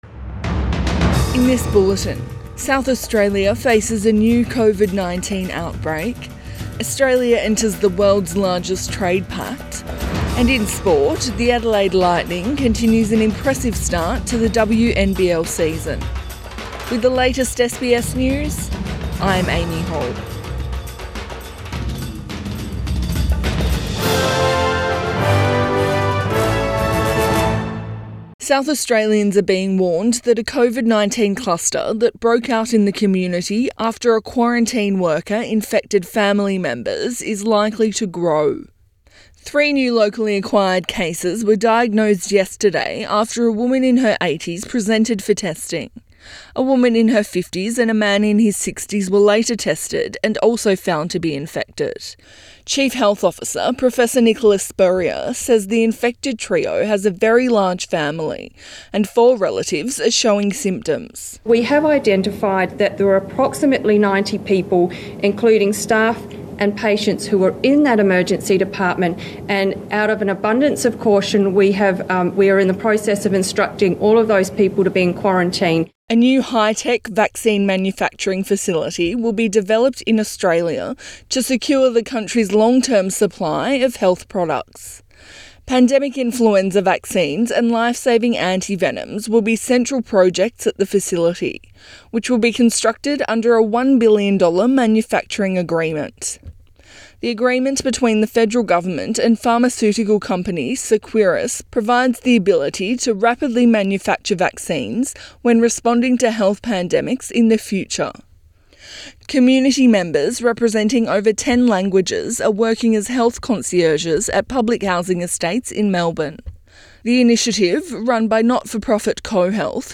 AM bulletin 16 November 2020